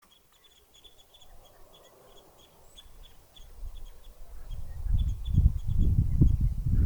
Птицы -> Вьюрковые -> 5
клест-сосновик, Loxia pytyopsittacus
Примечания/sākotnēji barojas eko priedē, atlido uz LOXPYT provokāciju, apsēžas eglēs, noteikti pēc saucieniem, gan vizuāli (visi pārskatīti), 'tīrs' bars - bez LOXCUR piejaukuma